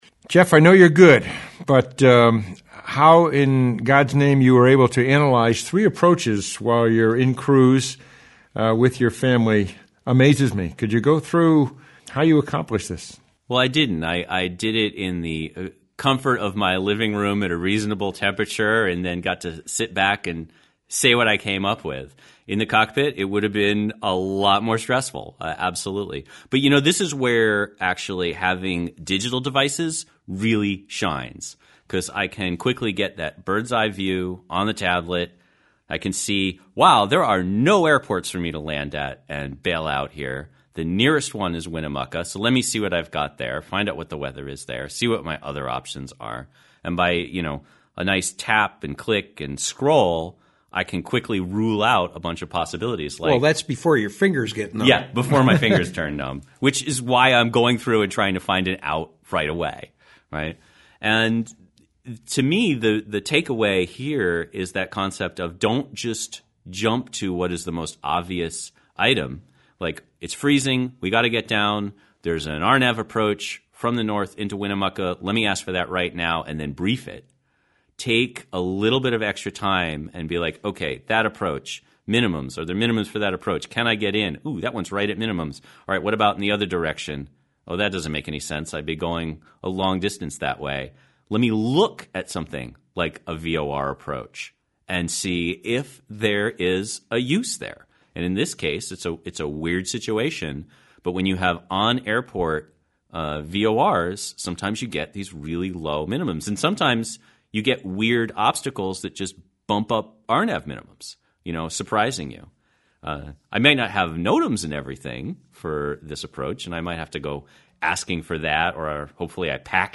75_winnamucca_roundtable.mp3